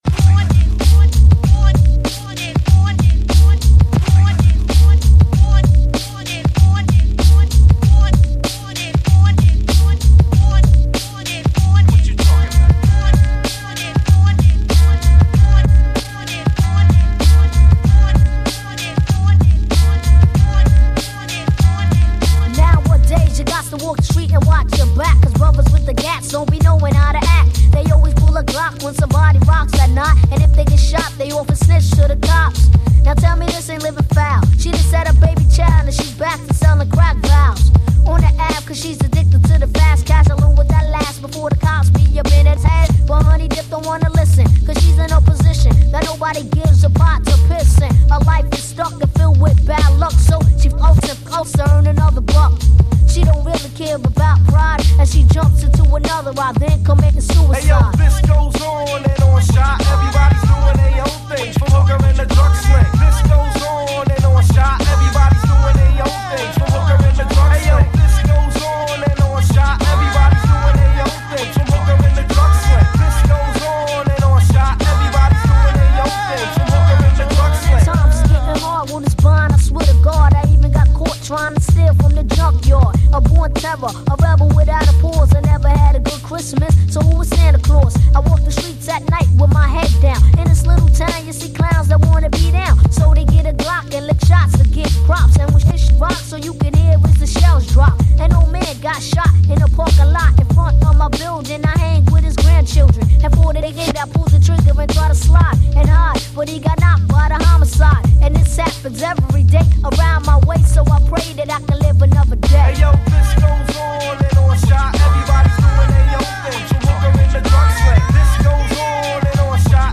A collection of recordings from hip-hop radio shows 1993 – 1998